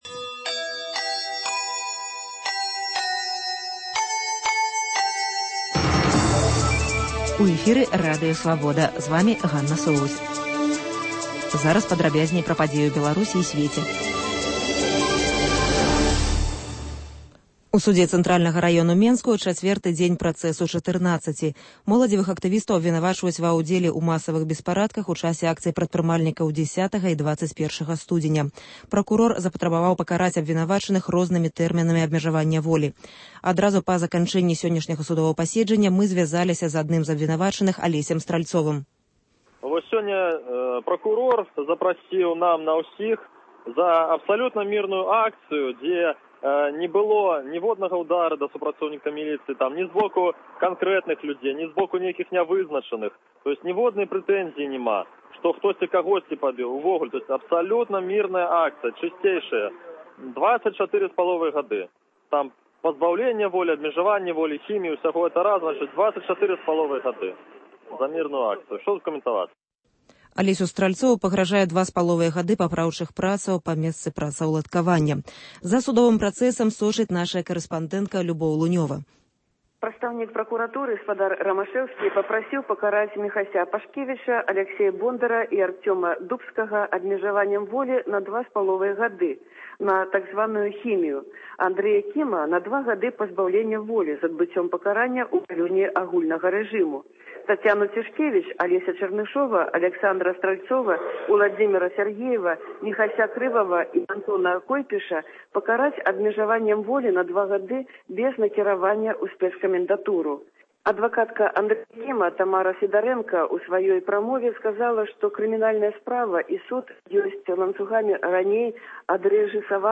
Паведамленьні нашых карэспандэнтаў, званкі слухачоў, апытаньні ў гарадах і мястэчках Беларусі.